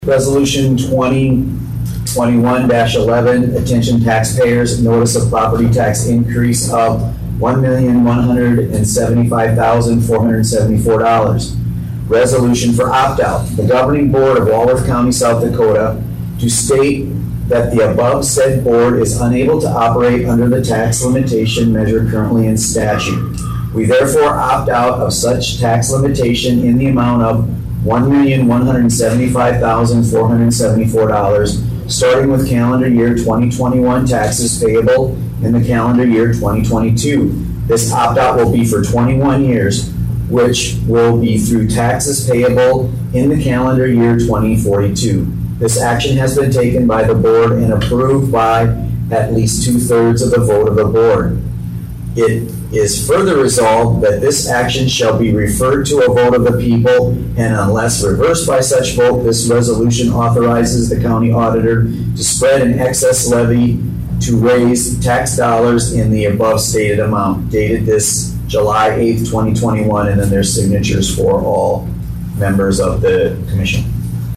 Hare read the resolution in its entirety.